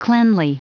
Prononciation du mot cleanly en anglais (fichier audio)
Prononciation du mot : cleanly